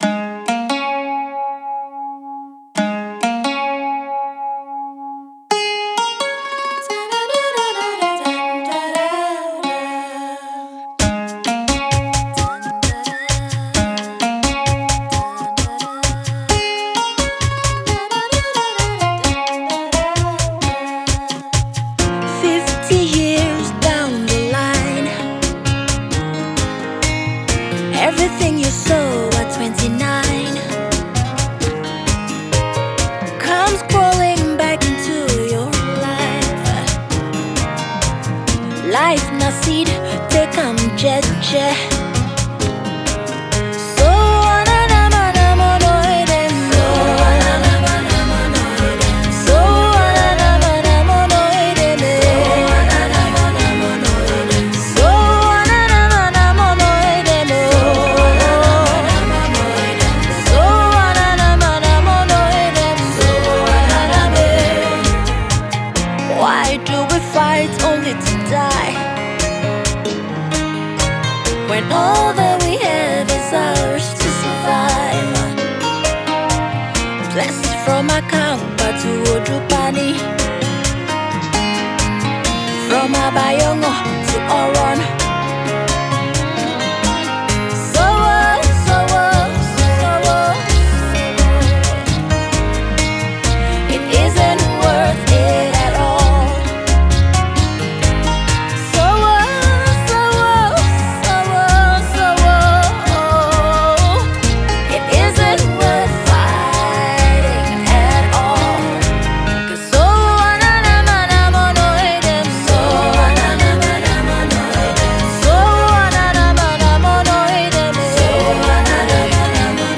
signature sultry vocals